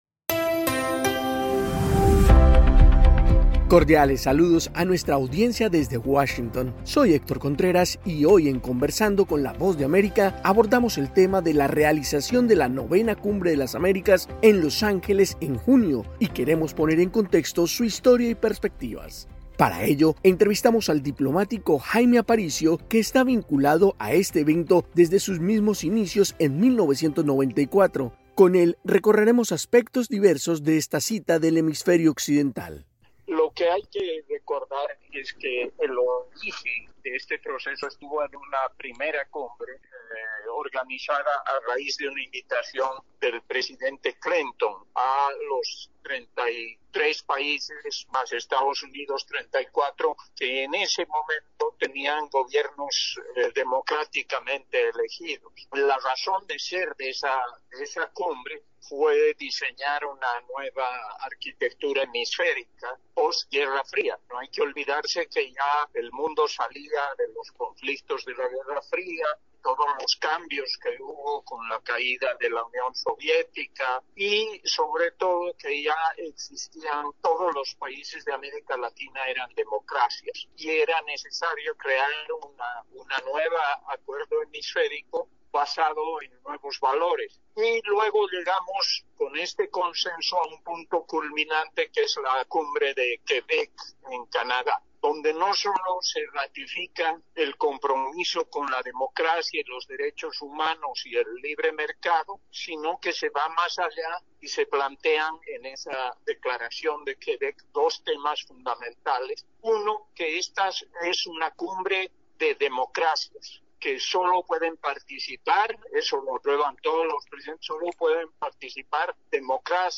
Conversamos con el diplomático Jaime Aparicio, exsecretario ejecutivo de la secretaria de Cumbre de las Américas, explicando la importancia de estos eventos y su aporte a la democracia de la región.